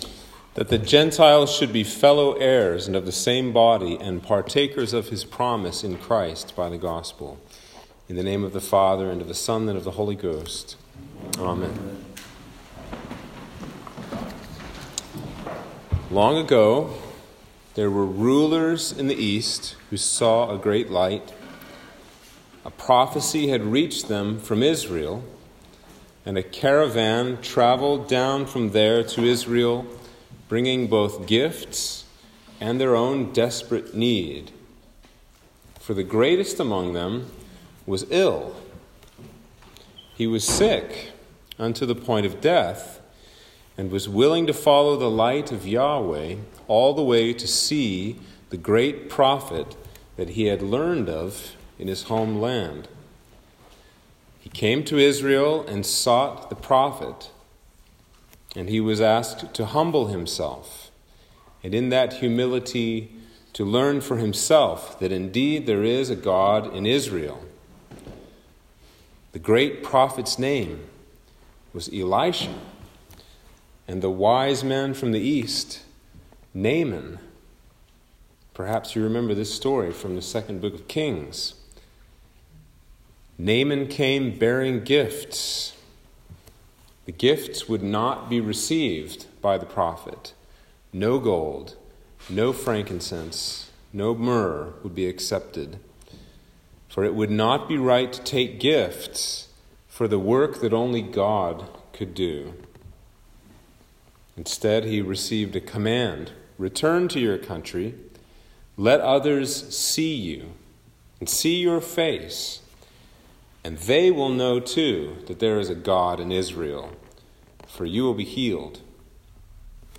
Sermon for Epiphany - January 6, 2022